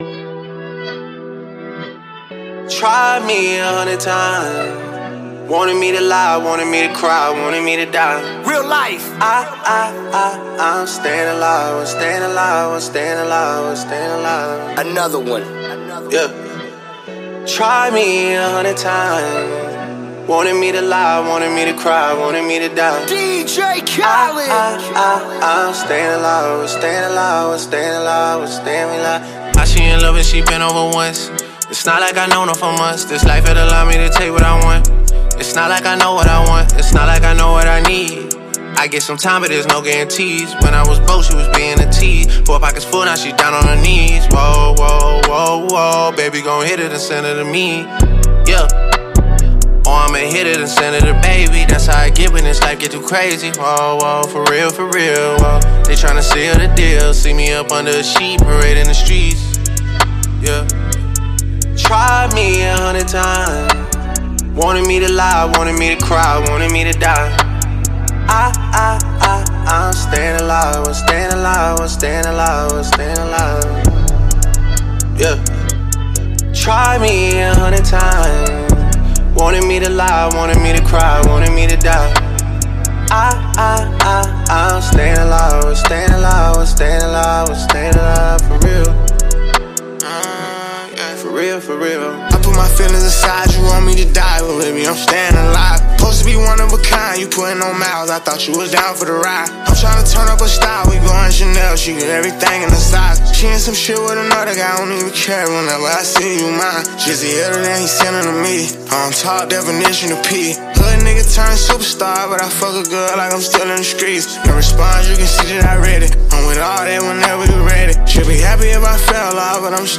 Each song has a strong beat and powerful lyrics.